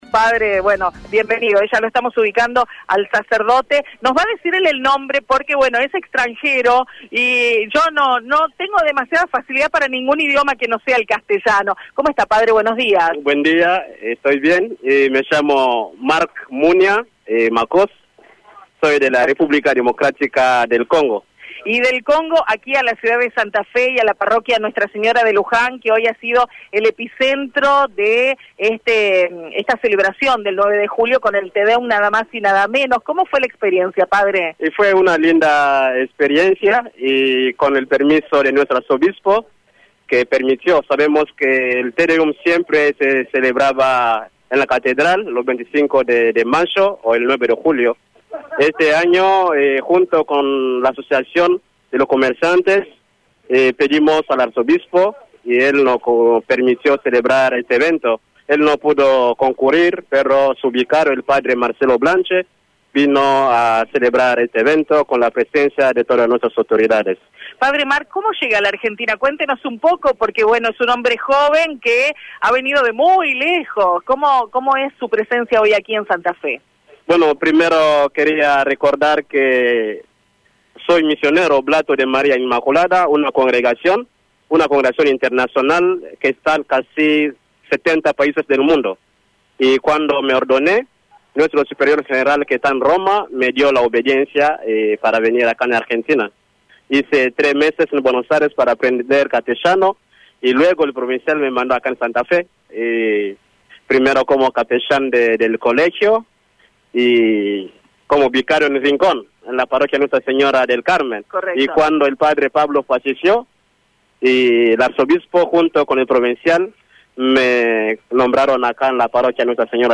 Día de la Independencia en Aristóbulo del Valle con radio EME en vivo